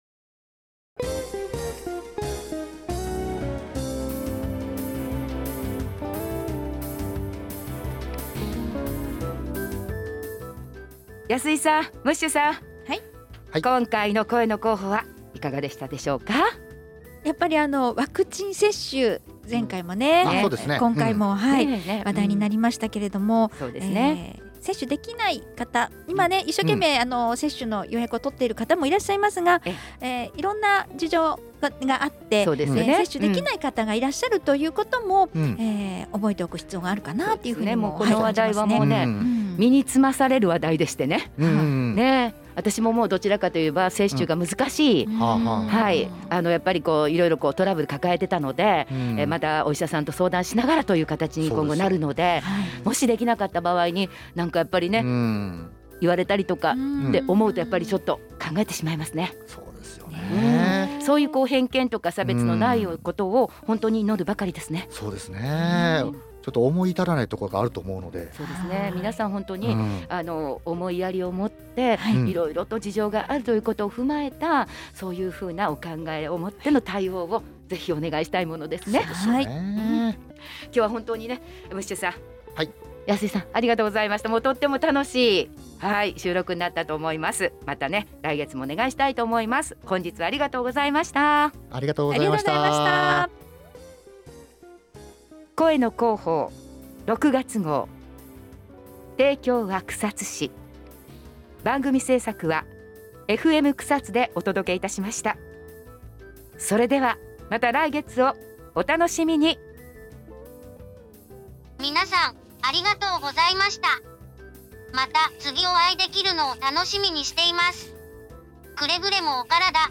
毎月発行される「広報くさつ」を、ＦＭラジオ放送でお届しています。